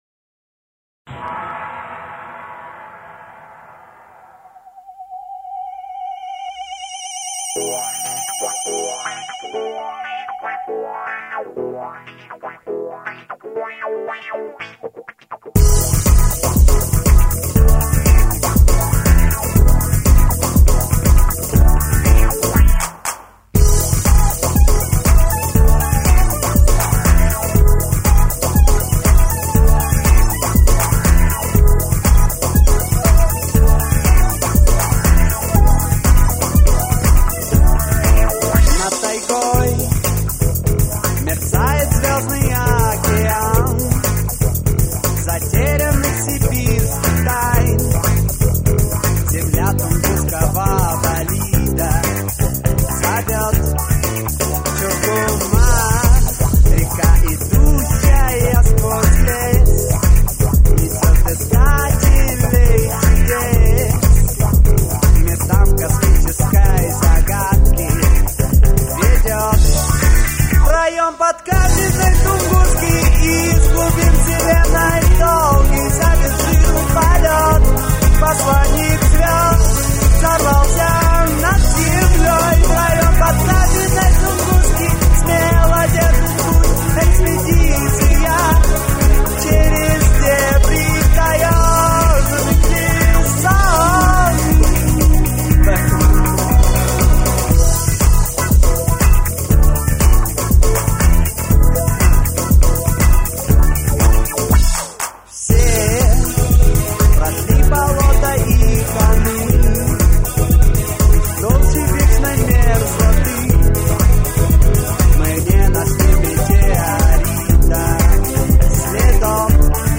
вокал
гитара
ударные
HOME RECORDING